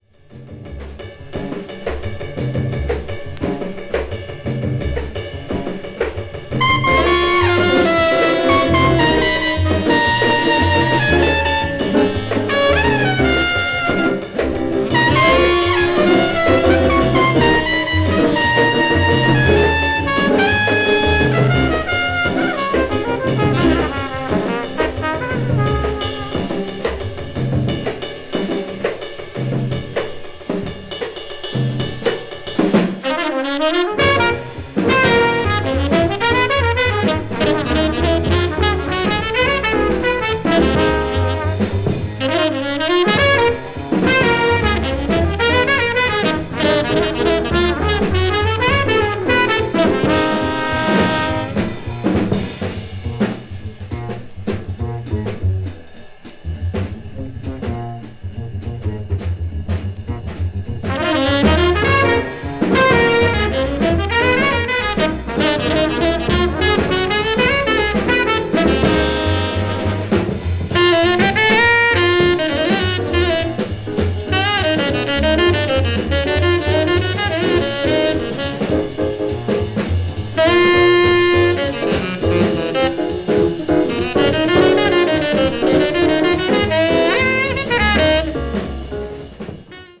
Mono
blues jazz   hard bop   modern jazz